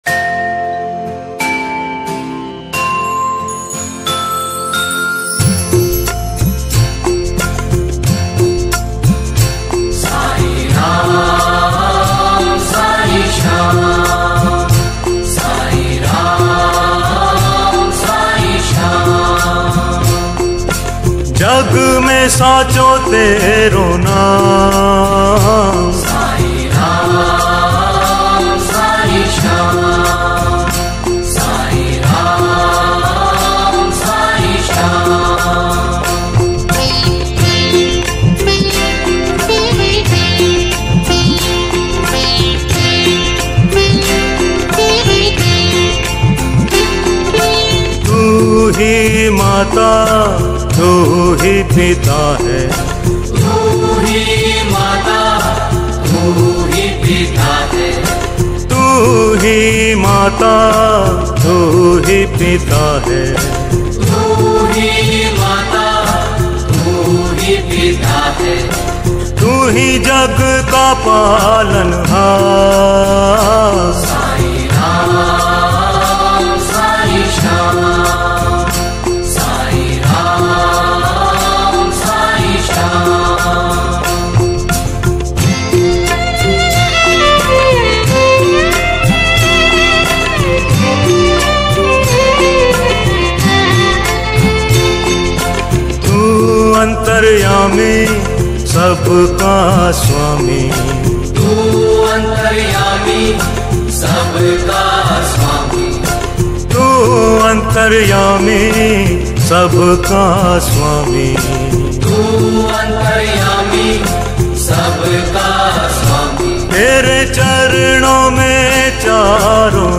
Bhajan & Aarti - Visit Shirdi
Tu Hi Mata Tu Hi Pita - Latest Sai Baba Bhajan.